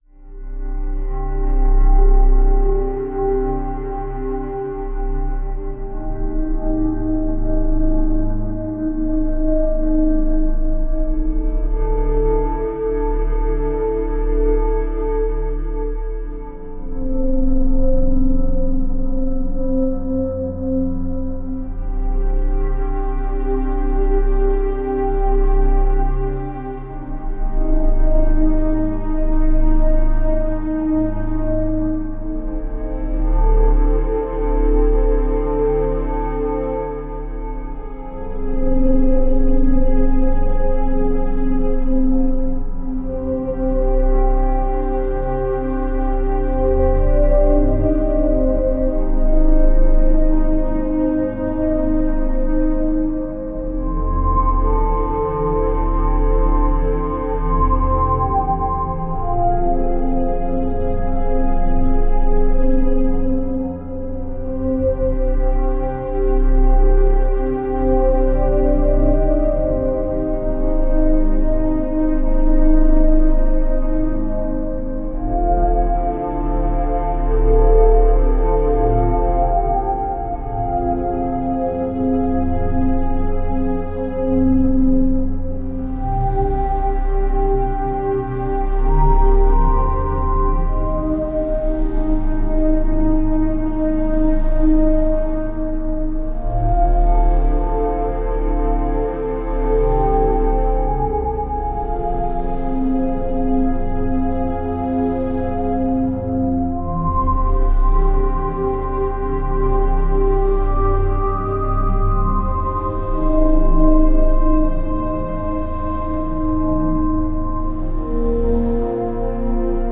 dreamy nostalgic